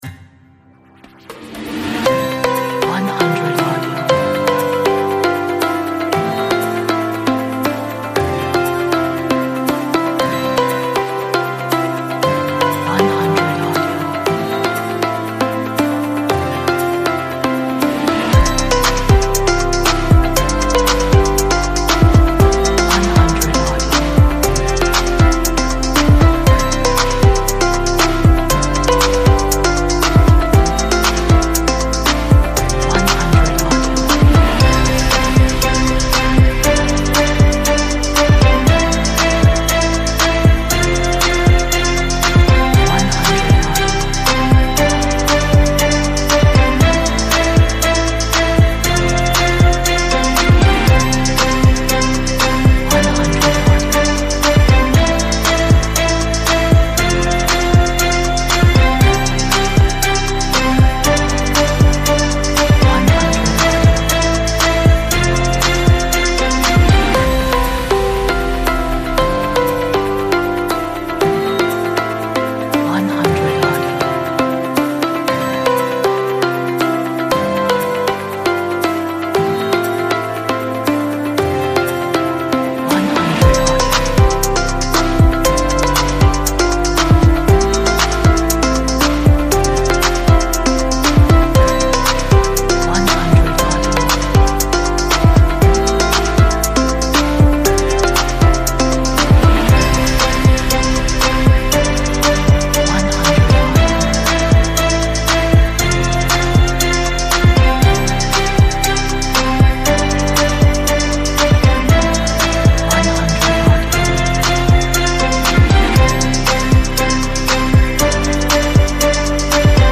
a nice pop inspirational track